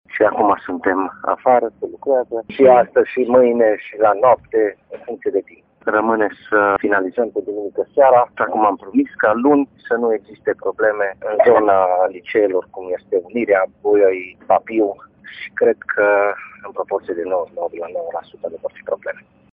Luni dimineața, odată cu începerea școlii, nu vor mai exista blocaje de trafic din cauza lucrărilor, ne asigură viceprimarul Municipiului Tîrgu-Mureș, Claudiu Maior: